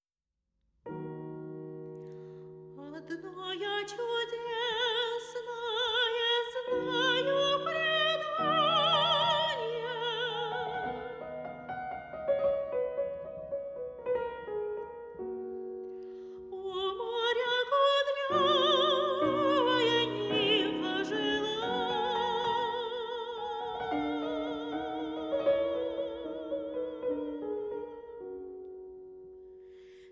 aufgenommen Herbst 2021 im Tonstudio